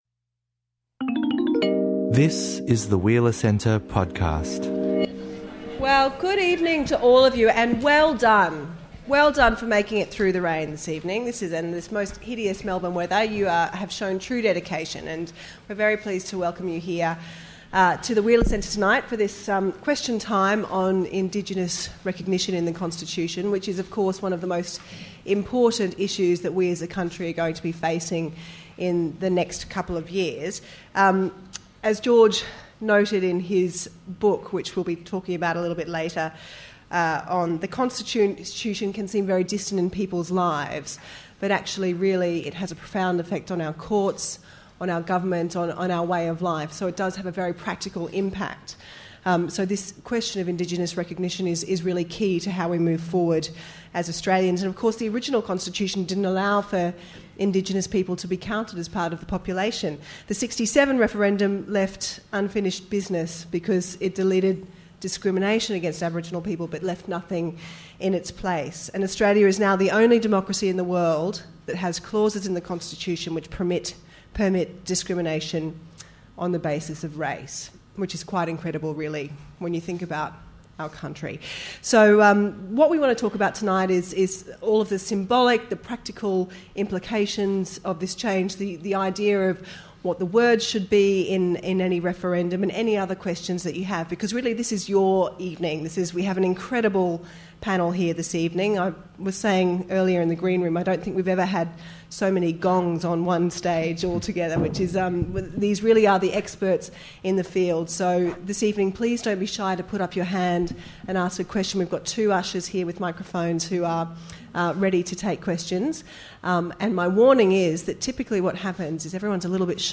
Question Time hands the discussion to you for a full hour of Q&A with our expert panellists.